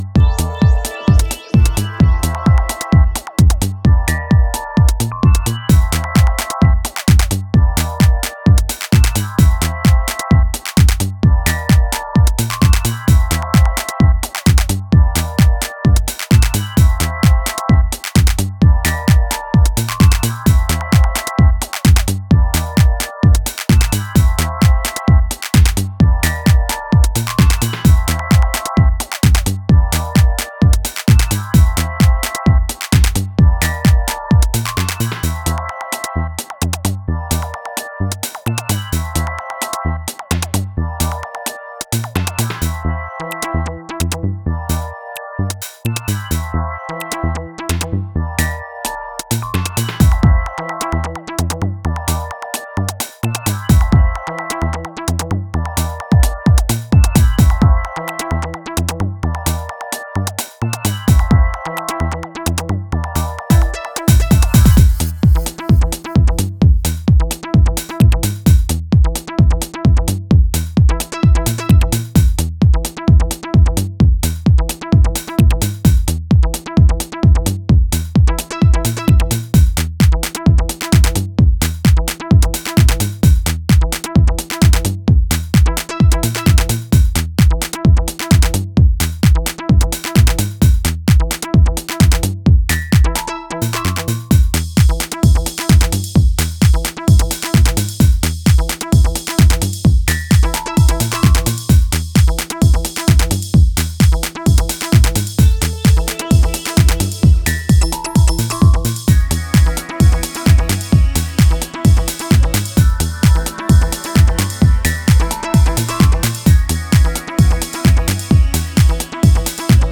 the Italian trio bring an electro yet hypnotic house vibe.